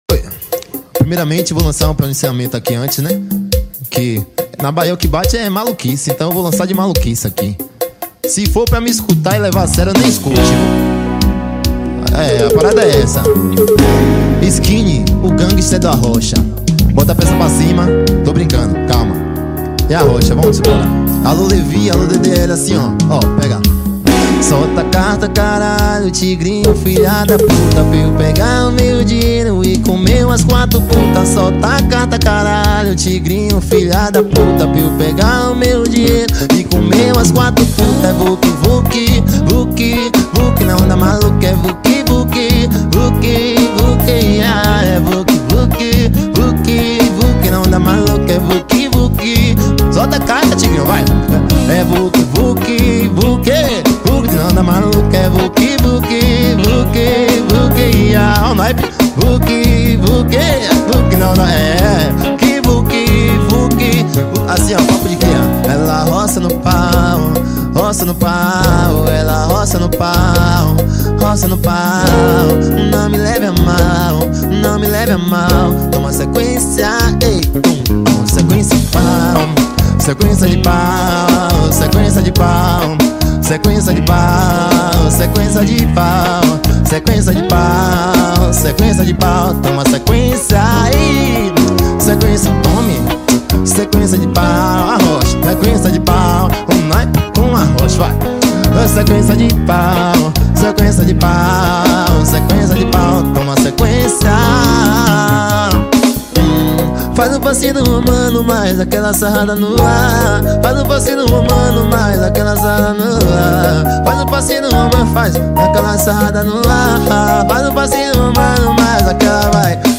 2024-12-29 16:55:03 Gênero: Arrocha Views